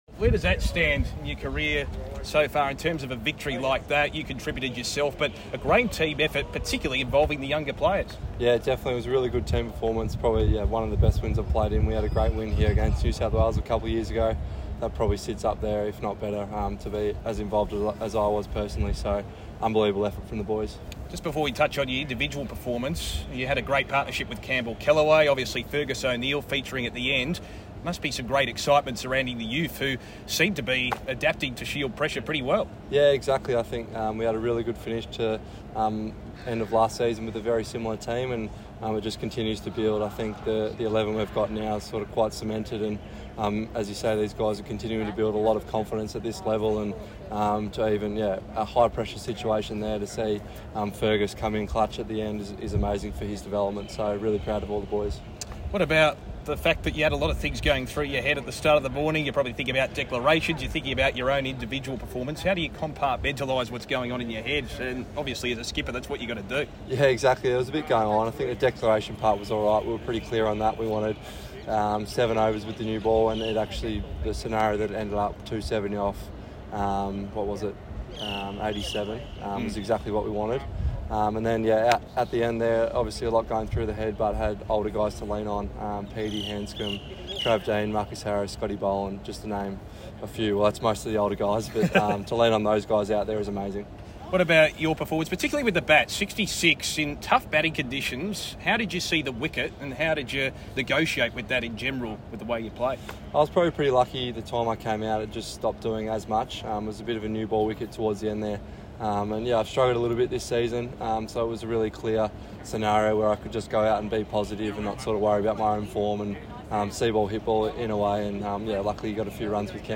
Victoria captain Will Sutherland (4-32) post game interview following win against Queensland